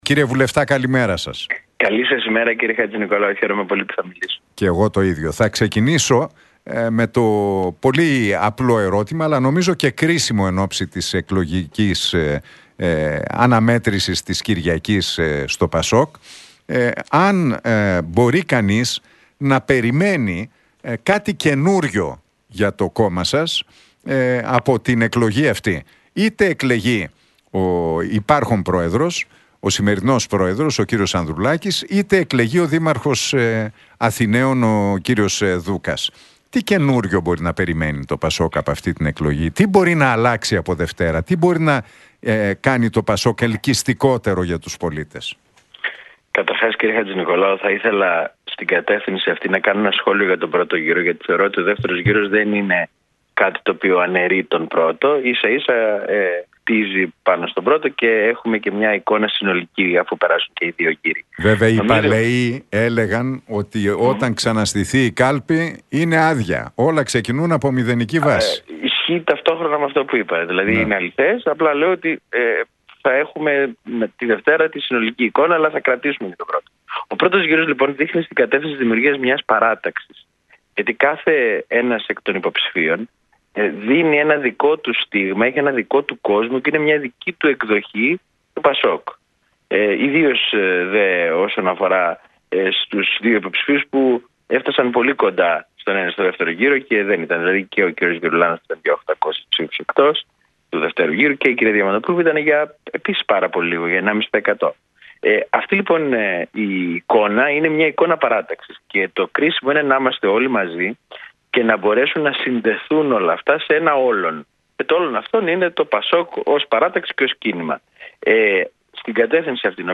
Για τον δεύτερο γύρο και την επόμενη μέρα στο ΠΑΣΟΚ, το ενδεχόμενο συγκυβέρνησης με την ΝΔ και συνεργασίας με τον ΣΥΡΙΖΑ μίλησε ο βουλευτής του ΠΑΣΟΚ, Παναγιώτης Δουδωνής στον Νίκο Χατζηνικολάου από τη συχνότητα του Realfm 97,8.